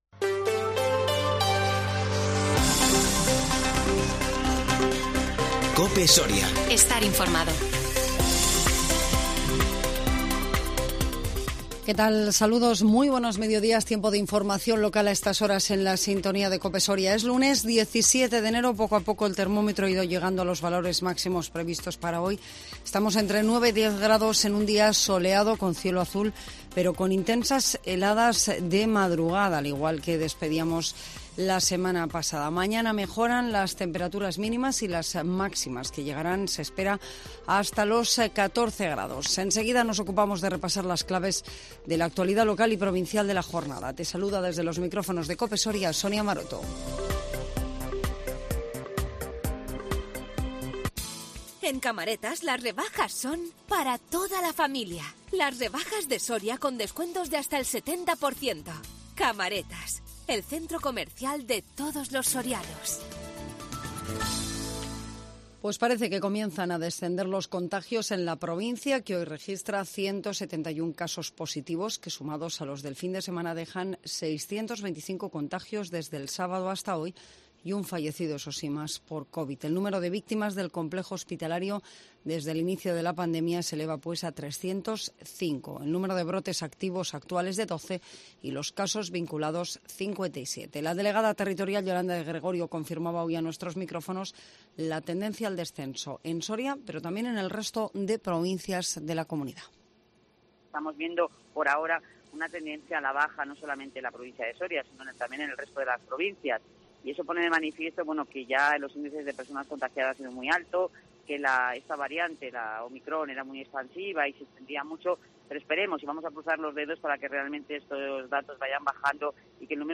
INFORMATIVO MEDIODÍA 17 ENERO 2021